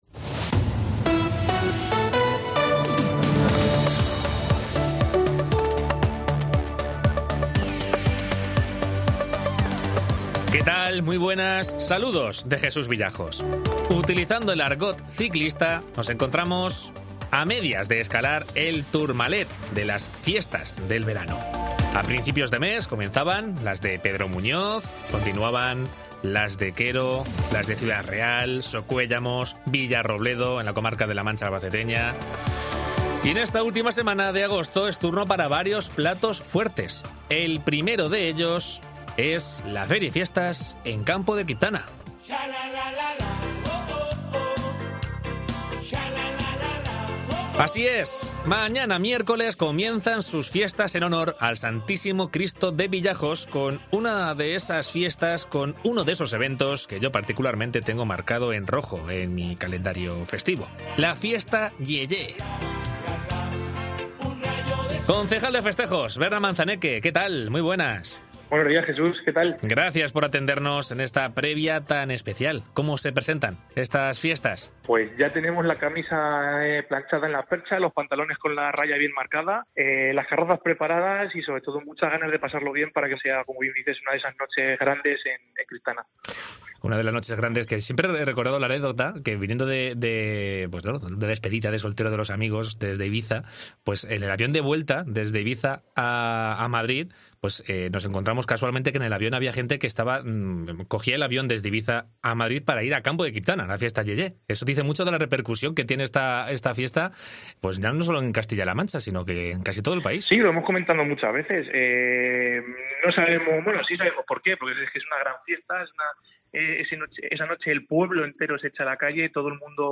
Entrevista a Berna Manzaneque, concejal de Festejos de Campo de Criptana